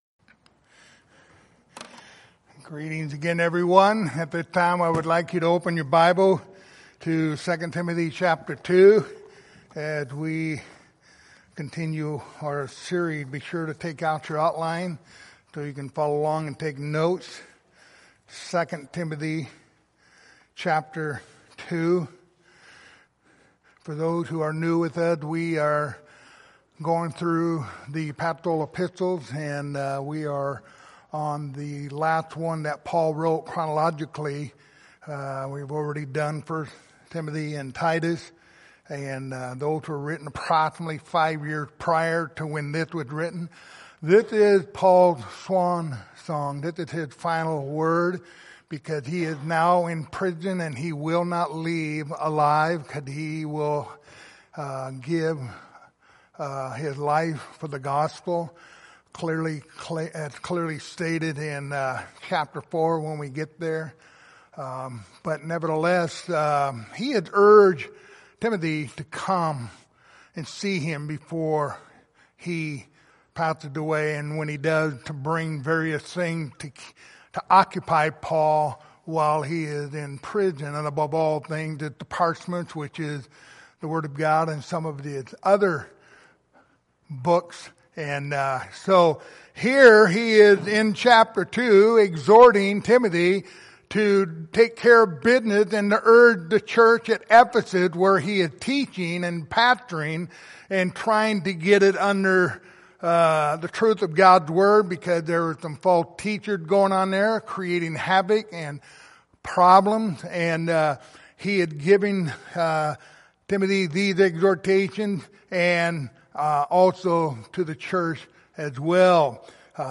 Passage: 2 Timothy 2:14-15 Service Type: Sunday Morning